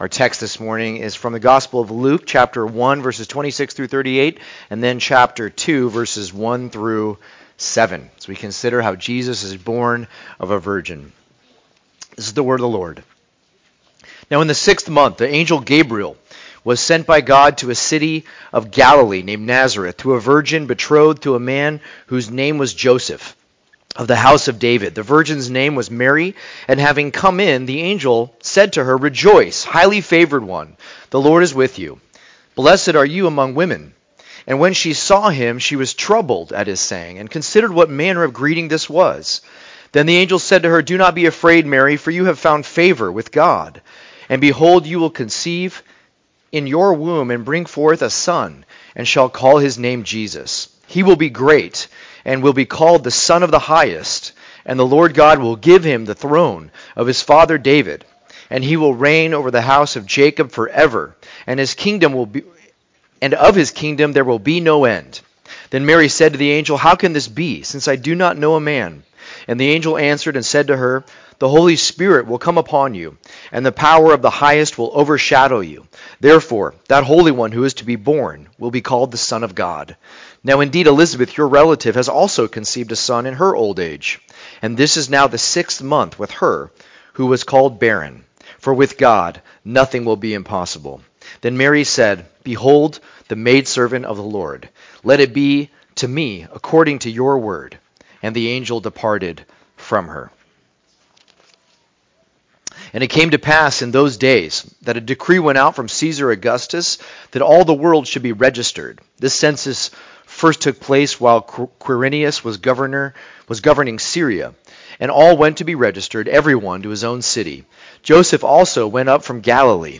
2025 Born of a Virgin Preacher